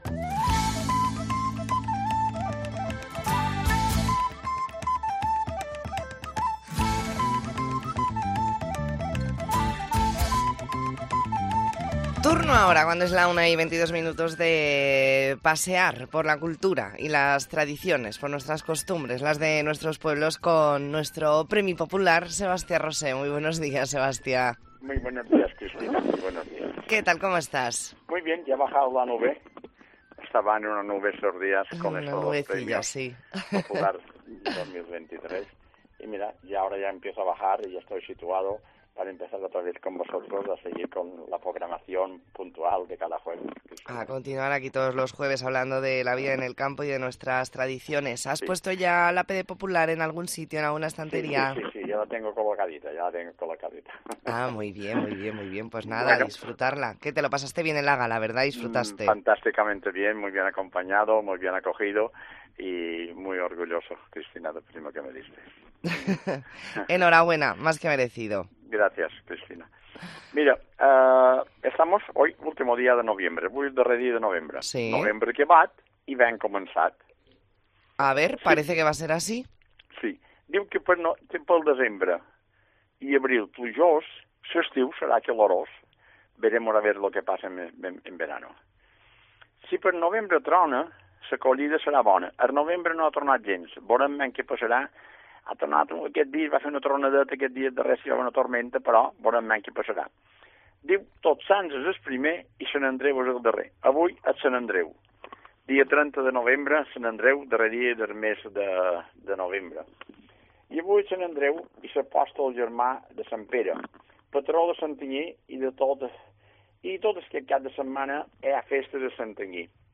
Espacio semanal donde repasamos las costumbres mallorquinas, el trabajo en el campo y os contamos las ferias y fiestas de los próximos días. Entrevista en 'La Mañana en COPE Más Mallorca', jueves 30 de noviembre de 2023.